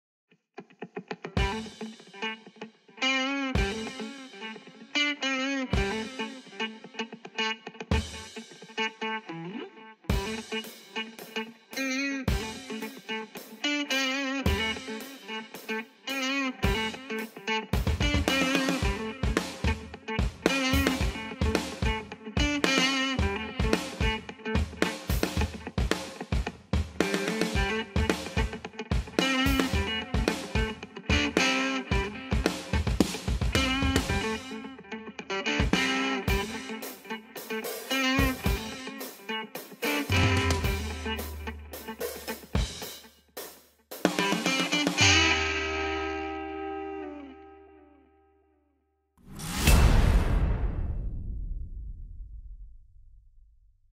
zwischen Type O Negative und Tiamat Ist es jazzy?